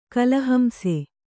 ♪ kalahamse